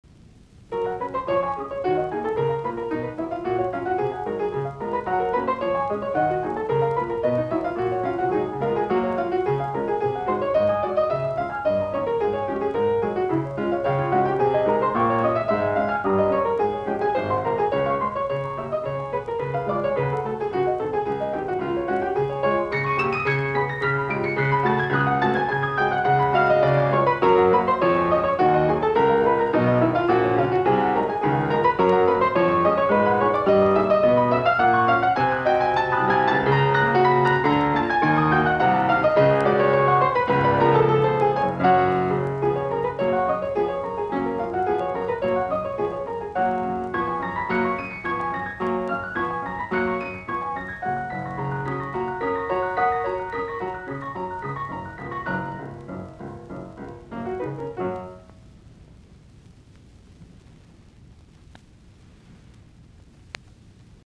Soitinnus: Piano.